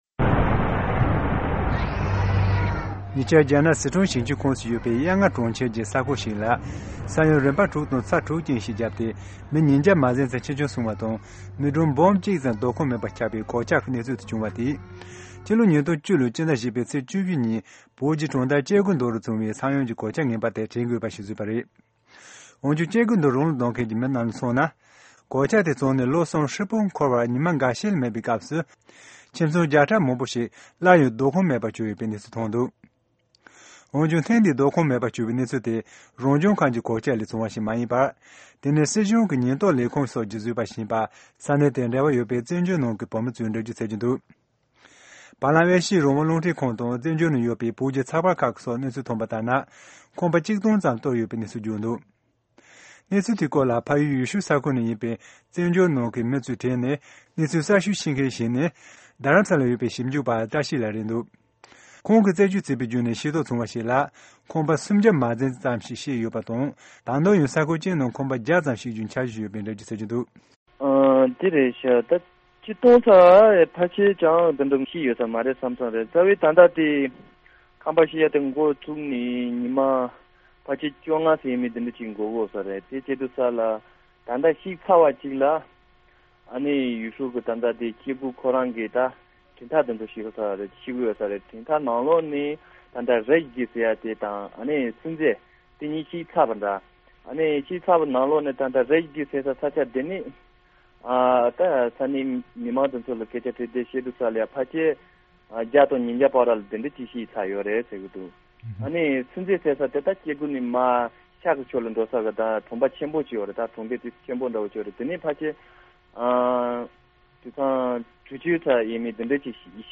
གནས་ཚུལ་དེར་རྒྱུས་མངའ་ཡོད་པའི་བོད་མི་ཞིག་ལ་གནས་འདྲི་ཞུས་ཏེ་ཕྱོགས་བསྒྲིགས་ཞུས་པ་ཞིག་གསན་གནང་གི་རེད།